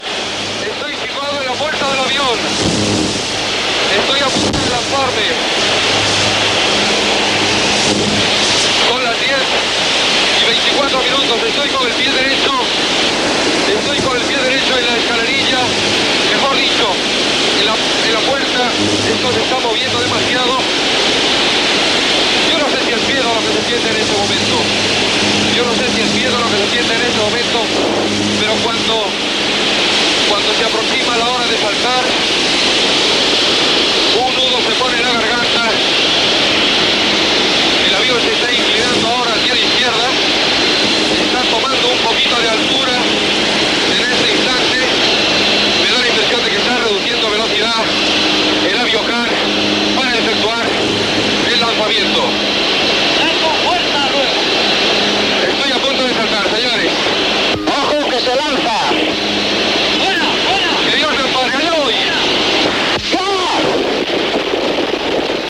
Narració de l'instant previ al salt.
Info-entreteniment